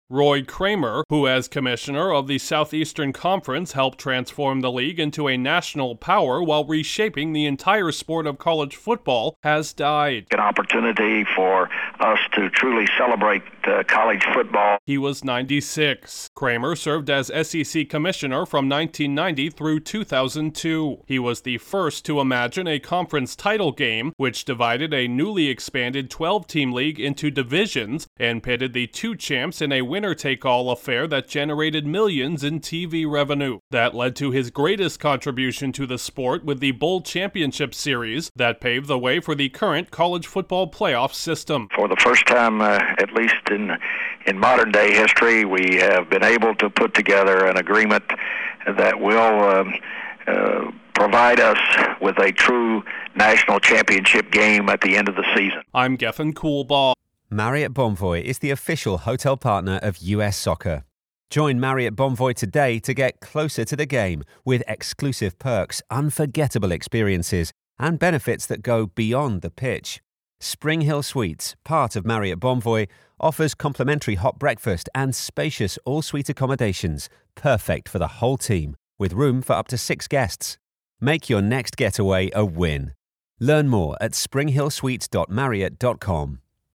A college football visionary who helped create the sport’s playoff has died. Correspondent